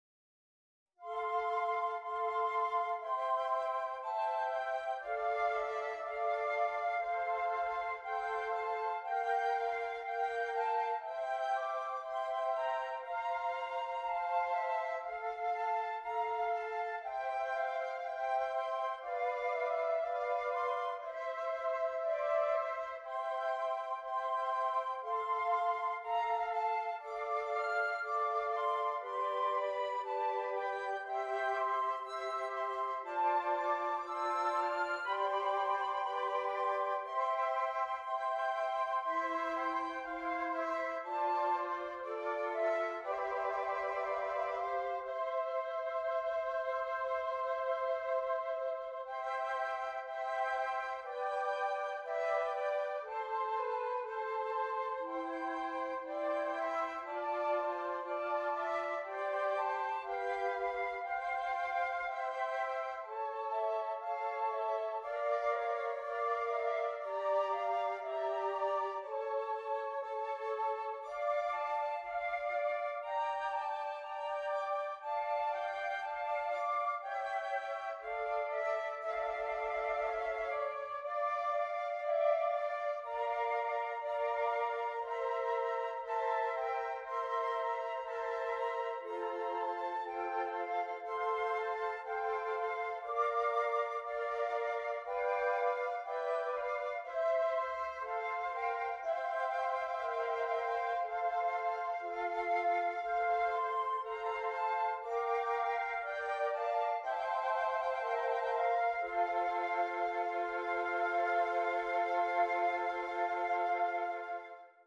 5 Flutes
The melody is passed throughout the ensemble